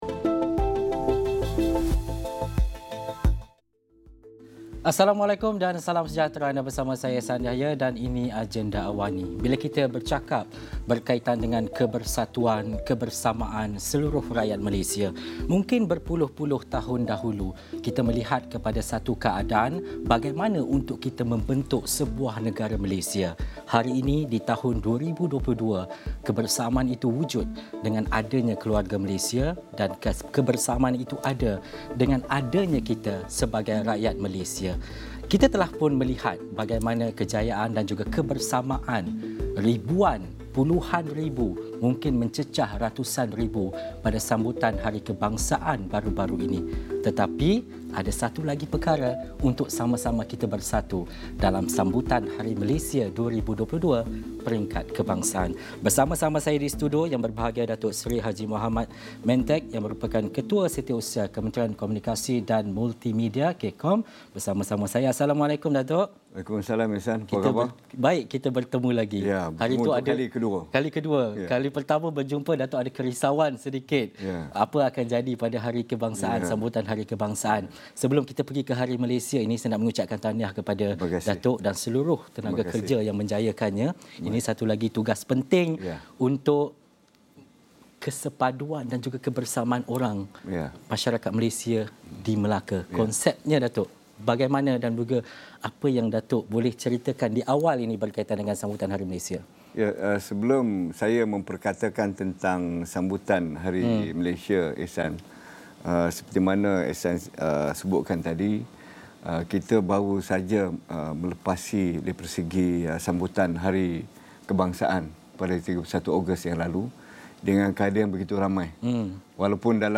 Ikuti temu bual bersama Ketua Setiausaha Kementerian Komunikasi dan Multimedia (K-KOMM), Datuk Seri Mohammad Mentek mengenai persiapan dan tumpuan pada sambutan Hari Malaysia 2022 yang akan berlangsung di Dataran Memorial Pengisytiharan Kemerdekaan di Banda Hilir, Melaka pada ...